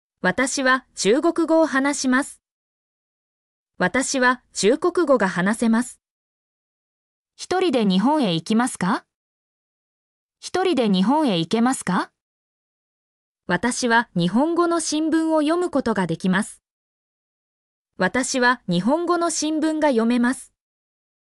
mp3-output-ttsfreedotcom-2_Gz29DkOQ.mp3